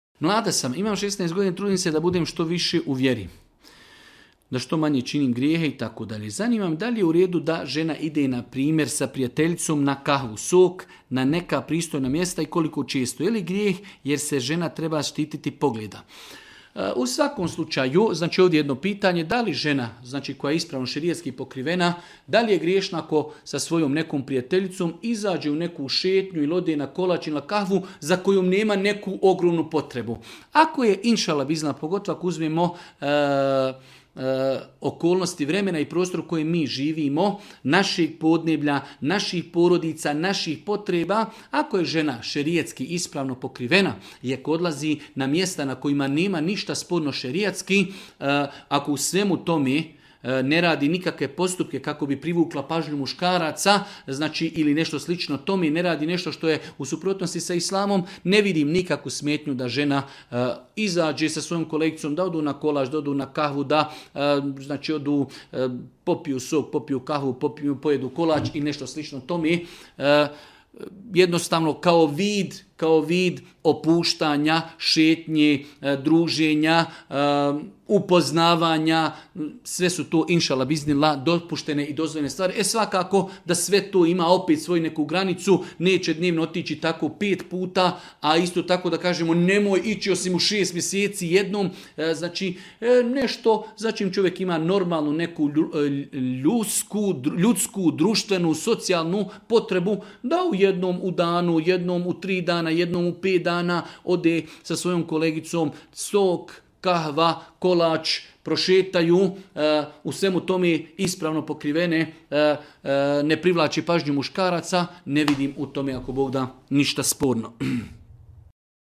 u video predavanju.